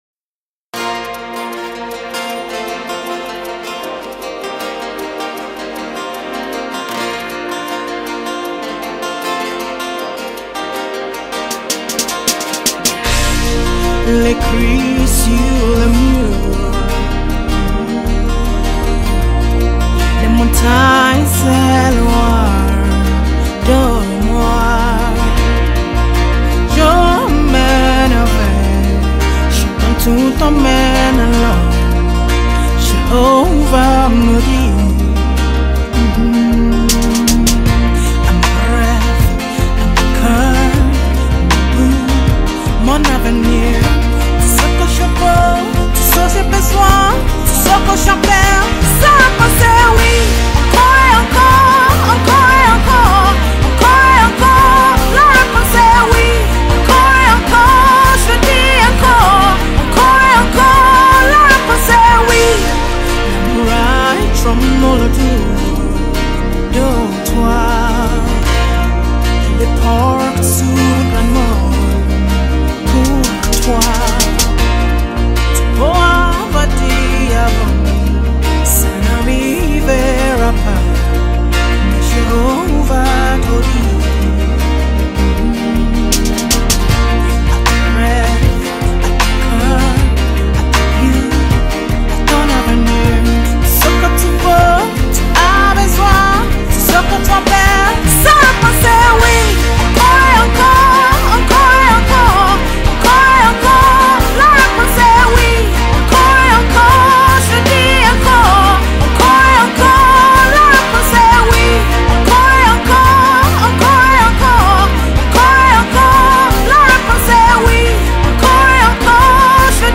a soulful melody that lifts your spirit
Genre:Gospel